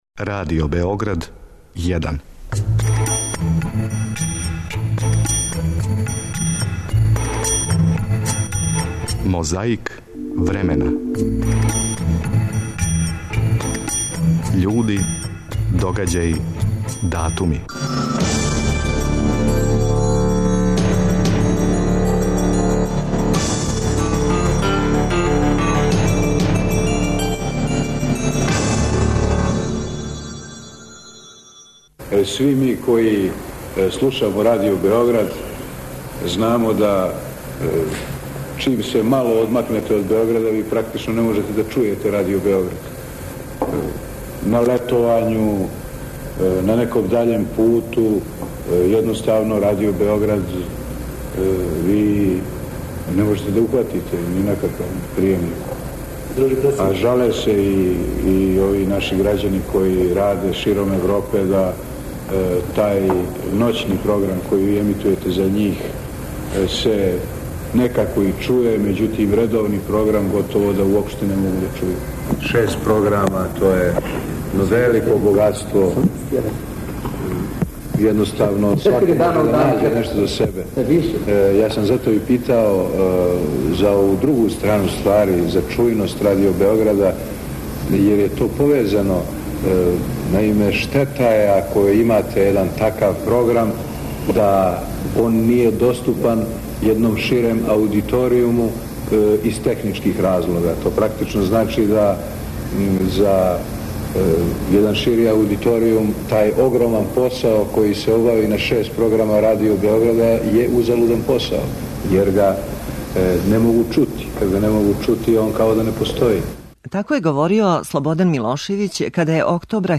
С јесени 1960. године Јосип Броз, друг Тито био је у Америци, а по повратку из Америке задржао се у Напуљу где је разговарао са новинарима разних медија, па и Радио Београда.
Подсећа на прошлост (културну, историјску, политичку, спортску и сваку другу) уз помоћ материјала из Тонског архива, Документације и библиотеке Радио Београда.